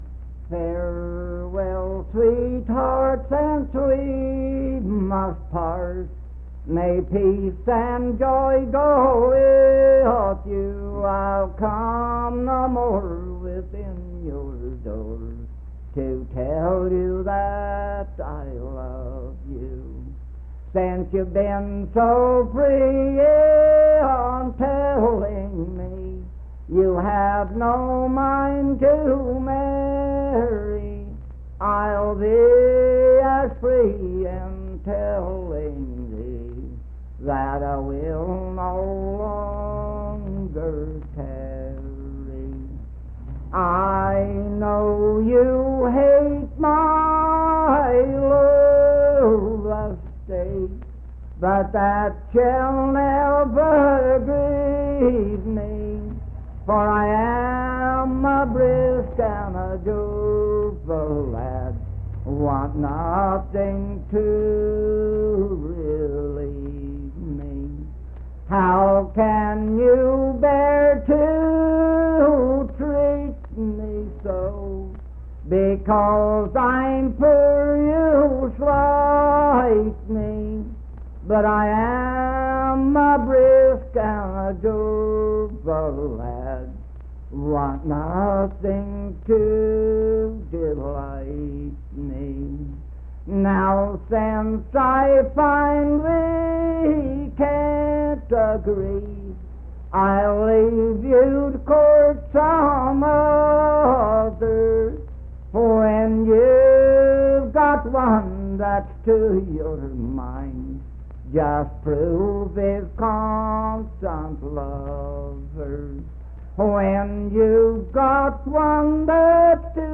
Unaccompanied vocal music performance
Voice (sung)
Spencer (W. Va.), Roane County (W. Va.)